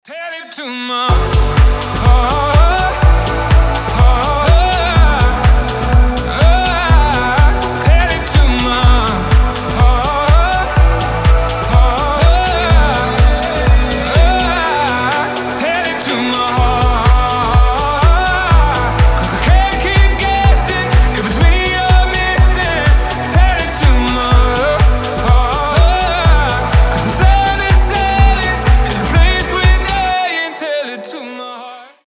• Качество: 320, Stereo
мужской голос
громкие
house